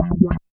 80 BASS LK-L.wav